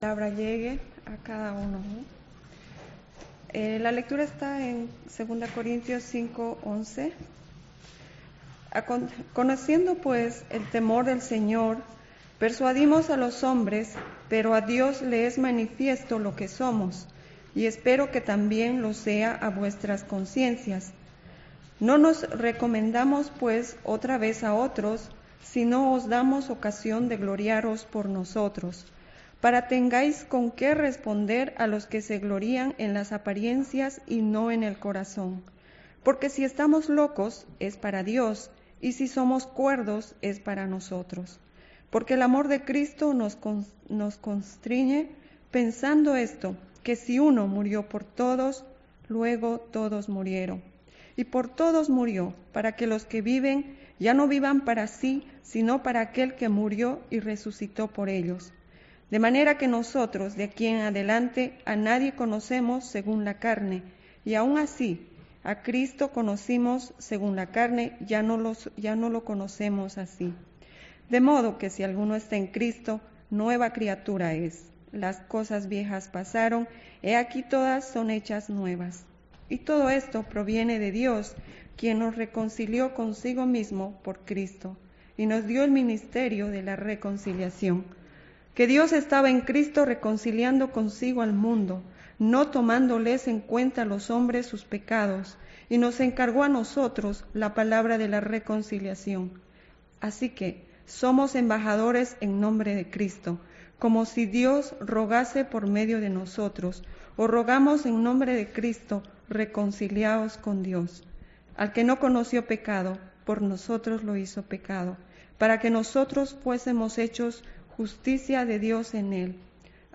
Current Sermon
Guest Speaker